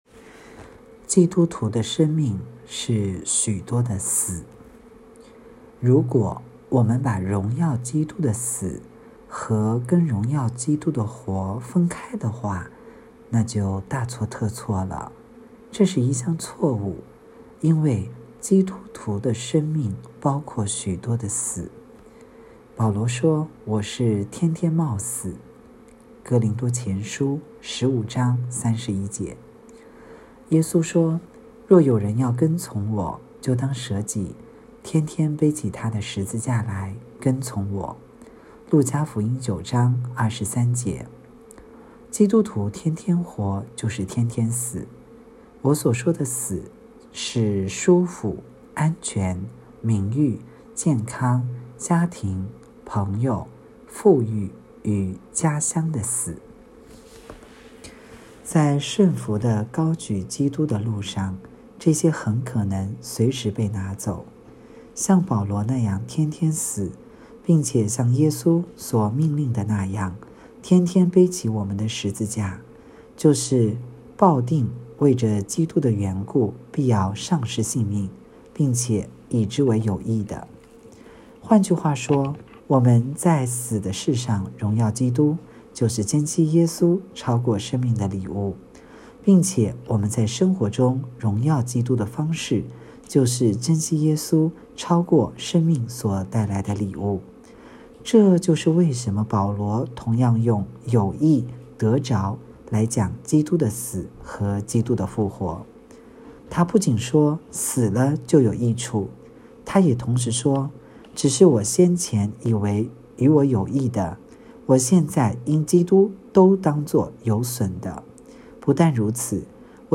2023年12月15日 “伴你读书”，正在为您朗读：《活出热情》 音频 https